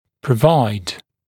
[prə’vaɪd][прэ’вайд]давать, предоставлять, обеспечивать